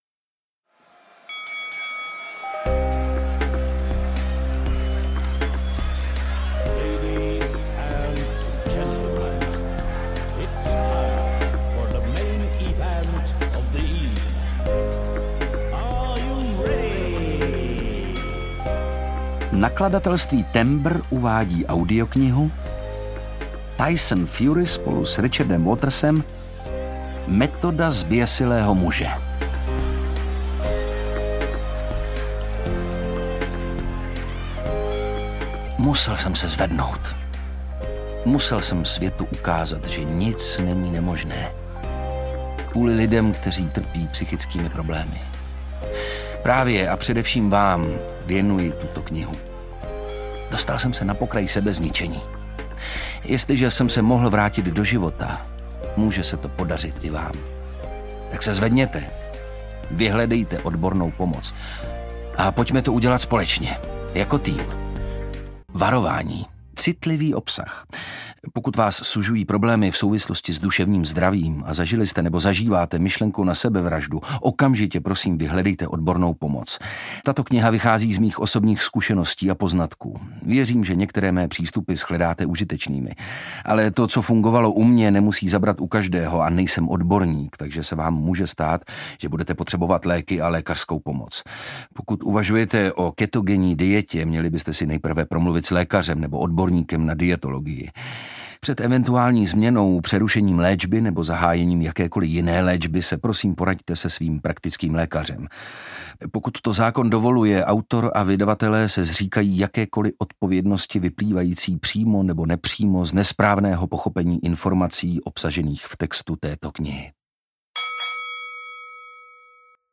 Metoda zběsilého muže audiokniha
Ukázka z knihy
• InterpretOndřej Brousek